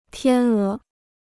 天鹅 (tiān é): swan.